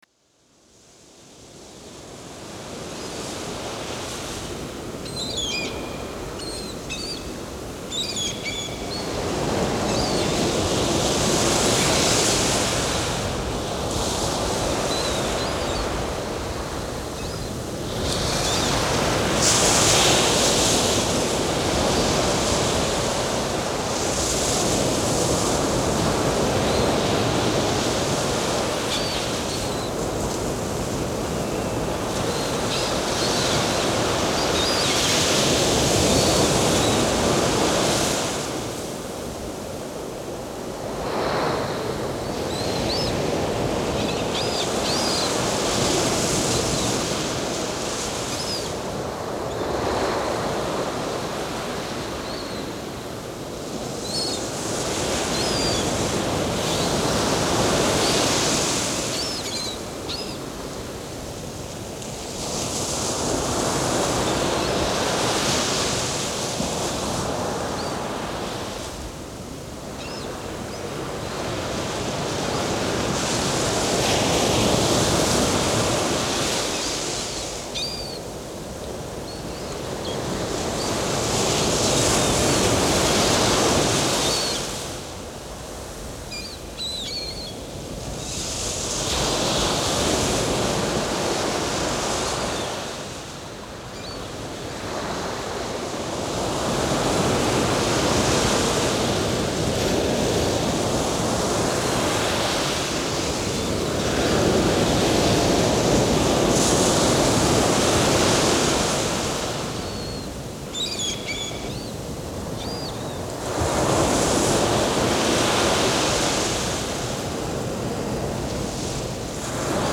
Морские птицы
Даже не верится,что эти звуки искусственные.